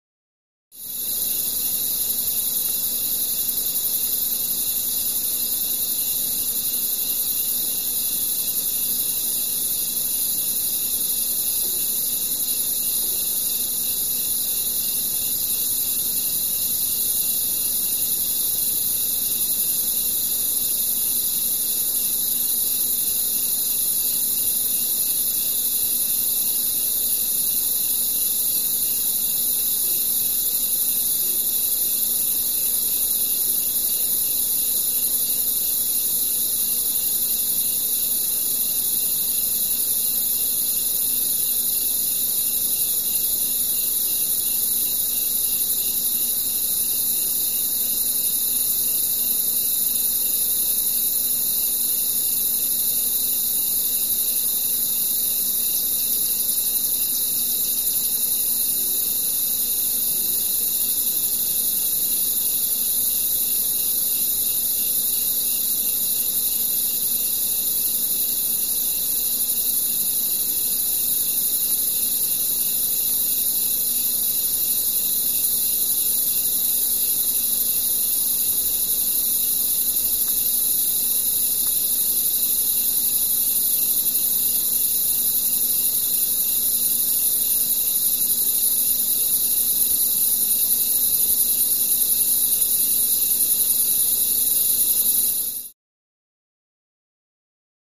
Insects; Night Atmosphere. Constant Insect Sizzle With Light Background Atmosphere.